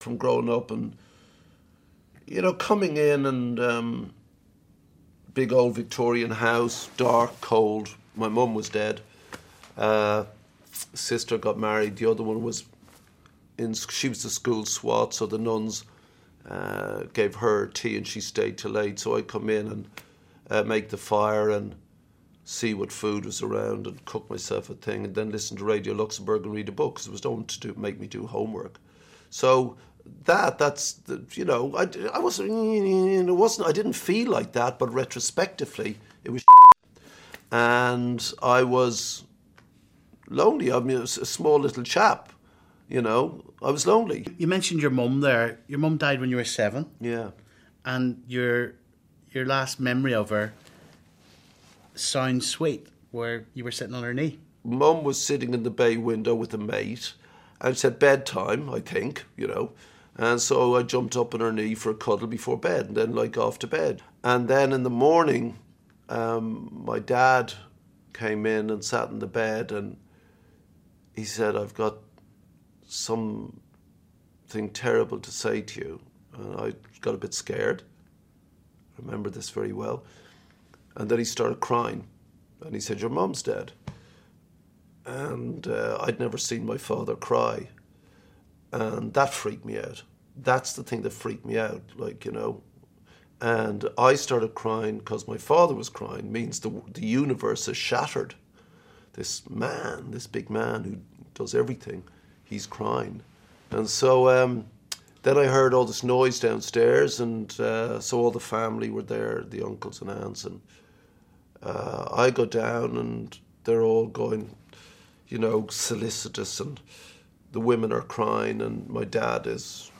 Part 2 of our Friday Interview with Bob Geldof